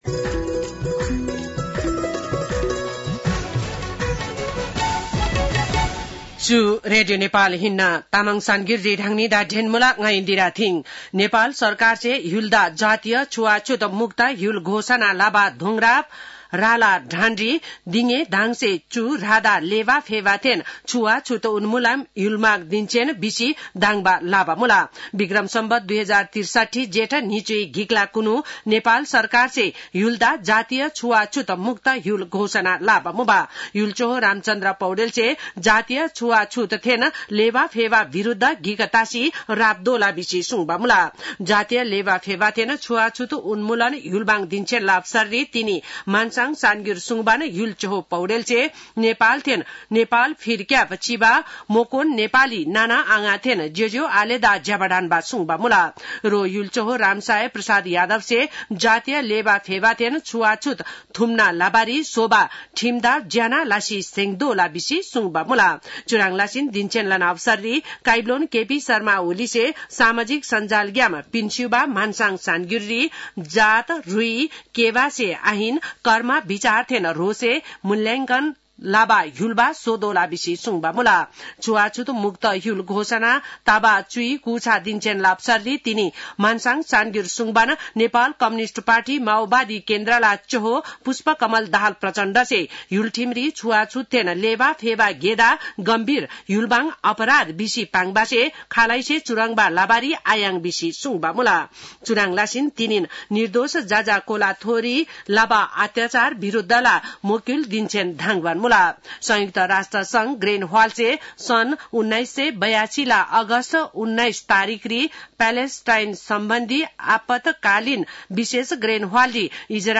तामाङ भाषाको समाचार : २१ जेठ , २०८२